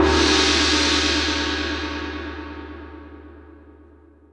Hats & Cymbals
Cymbal_China.wav